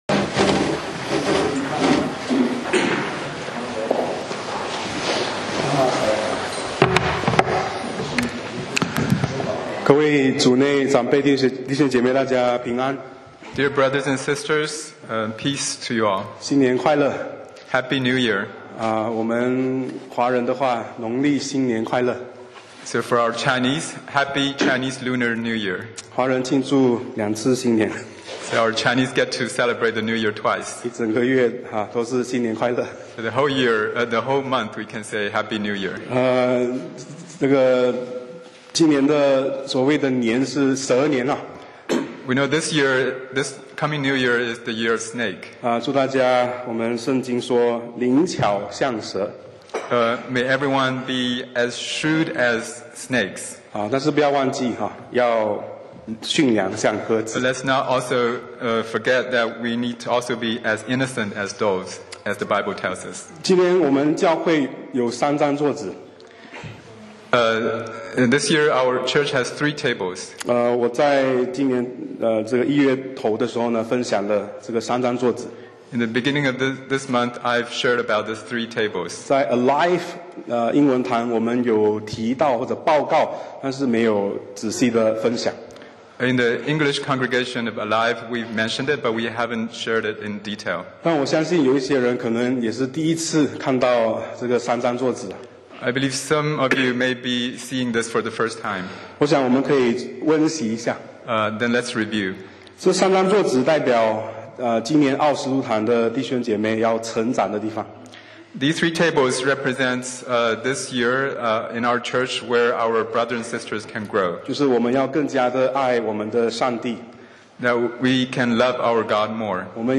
講道 Sermon 題目 Topic： 一个更新的教会 經文 Verses：罗马书 12：1-2。